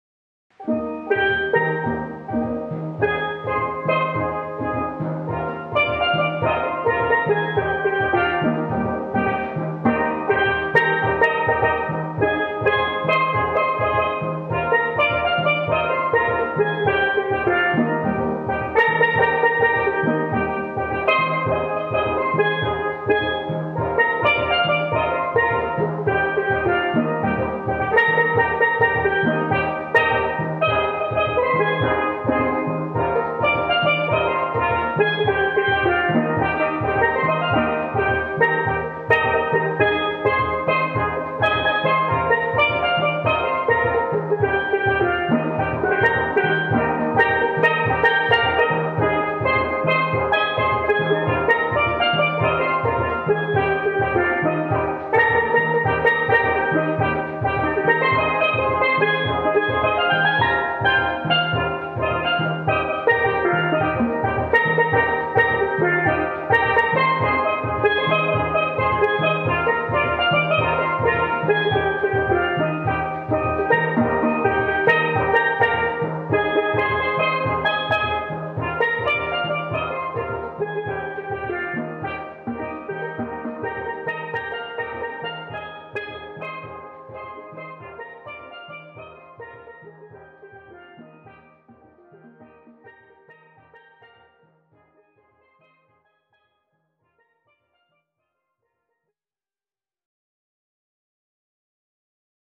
steelband